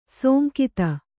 ♪ sōnkita